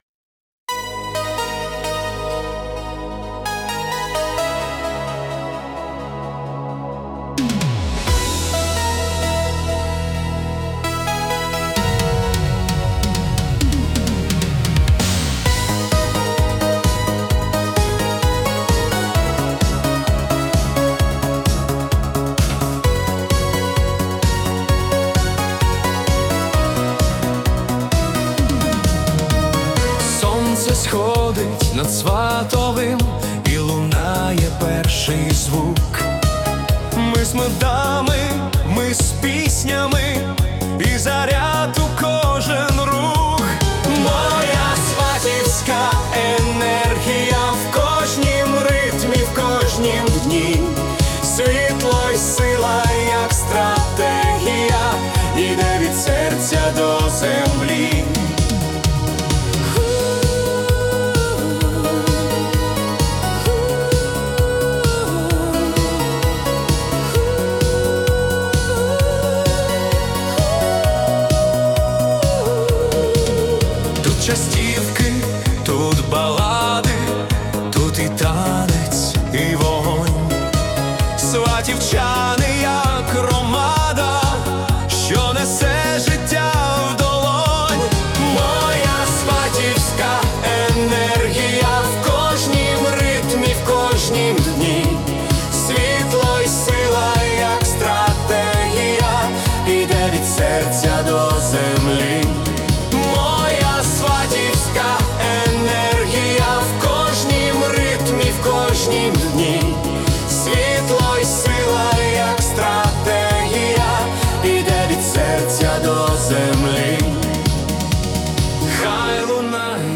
🎵 Жанр: Italo Disco Anthem
(129 BPM)